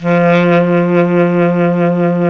Index of /90_sSampleCDs/Roland L-CDX-03 Disk 1/SAX_Baritone Sax/SAX_40s Baritone
SAX B.SAX 07.wav